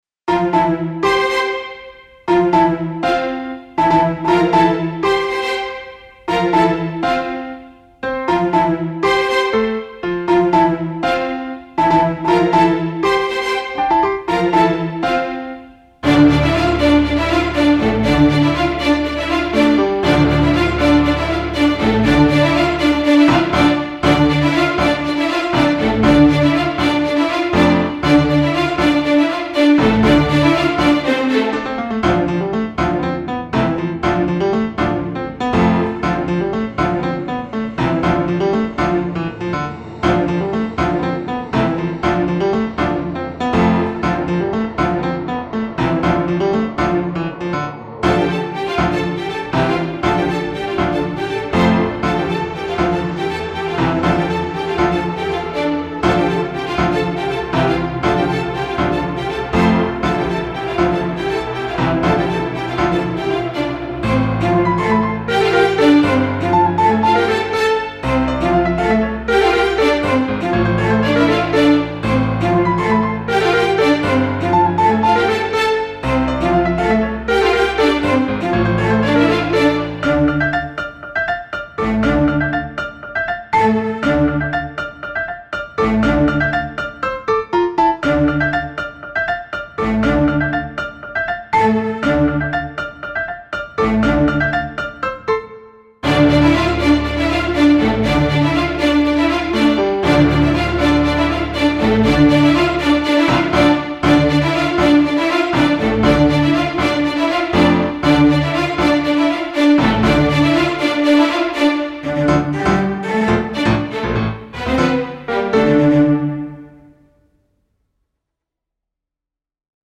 והפעם הסגנון מוזיקה שונה מאוד מהרגיל